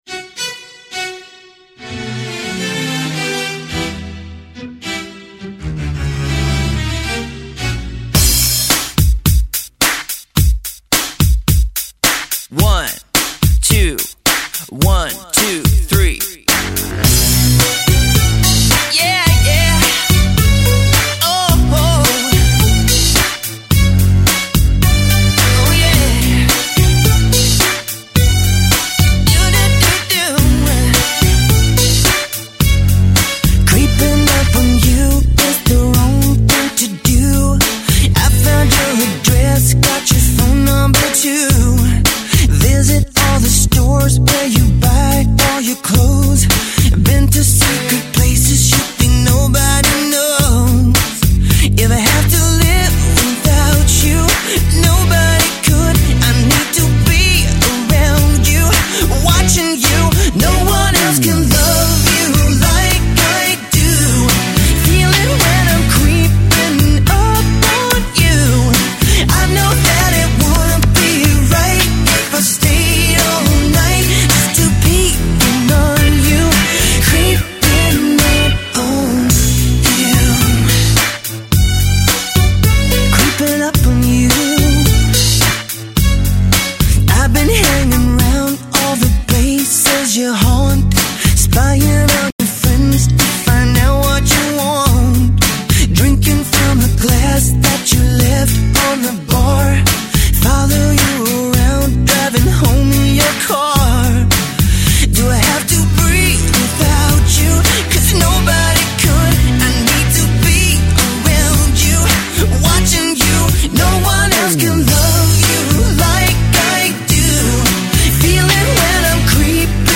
极品发烧 汽车音乐典范